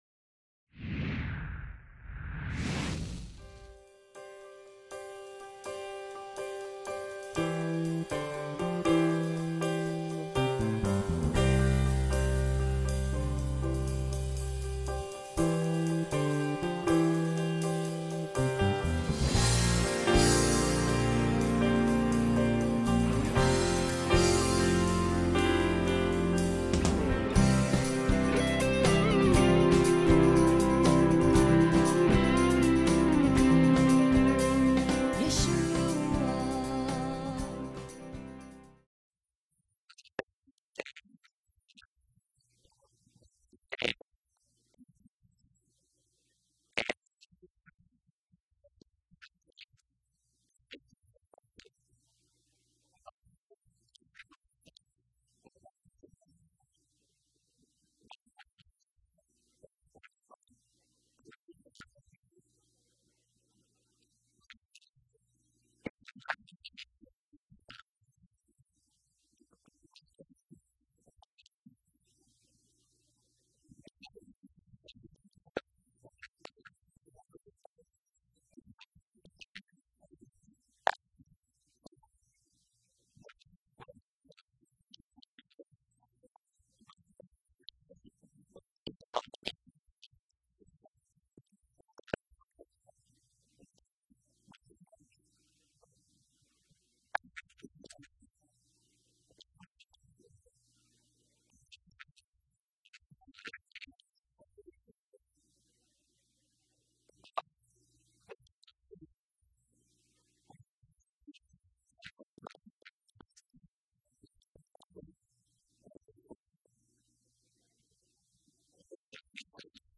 Lesson 34 – Daniel 12 (End of Book)